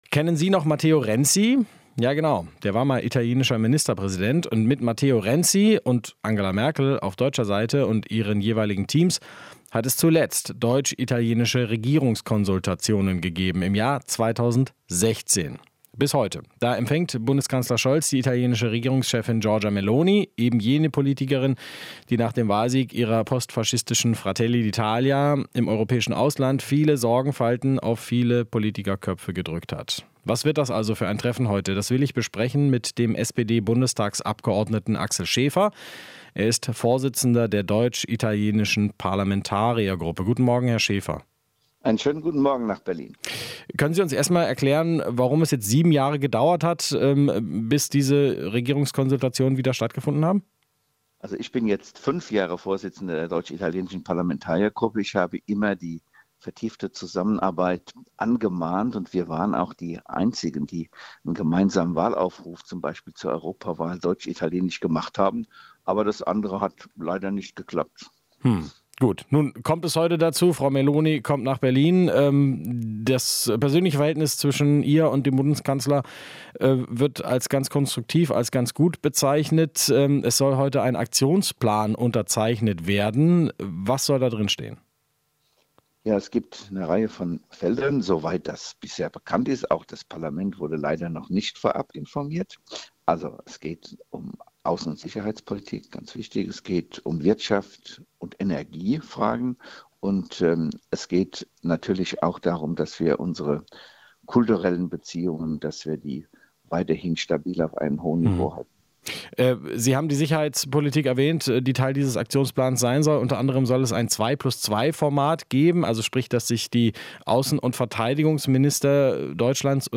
Interview - Schäfer (SPD): Meloni muss sich beim Thema Migration bewegen